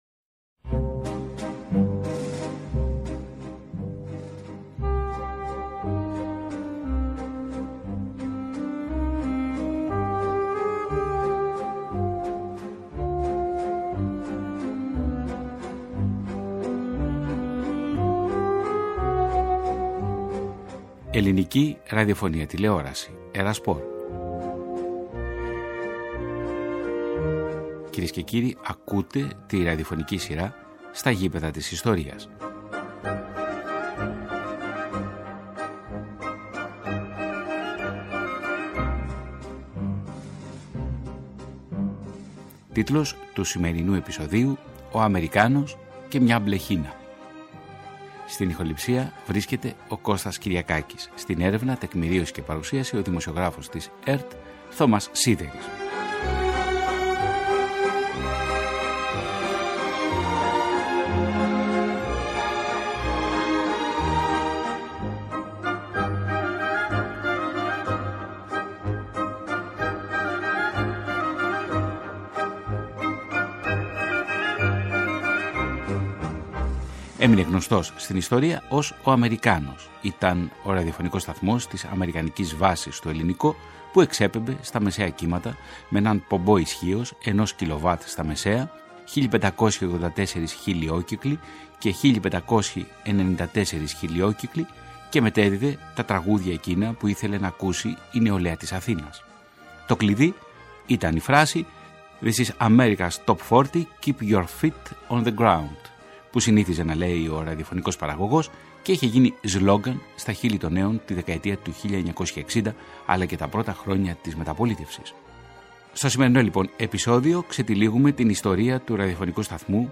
Ακούγονται ηχητικά τεκμήρια από τις εκπομπές του «Αμερικάνου» , αλλά και από το Αρχείο της ΕΡΤ .